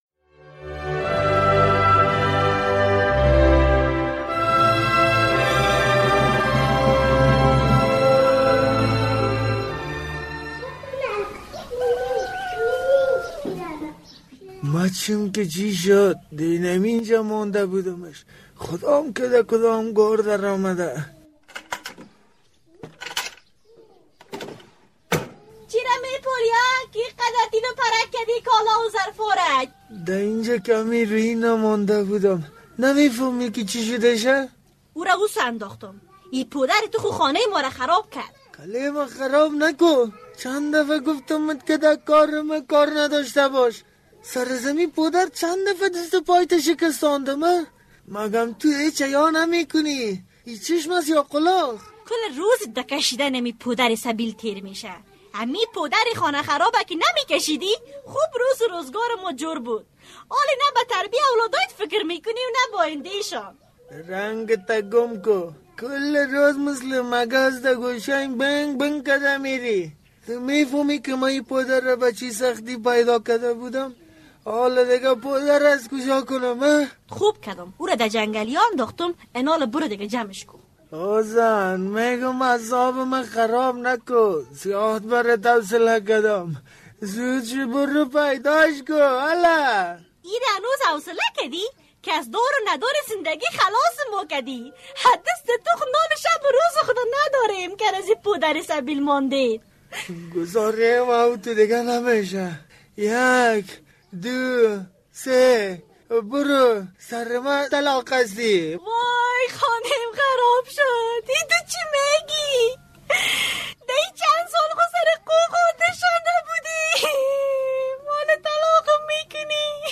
درامه: زنده‌گی جبار چرا تباه شد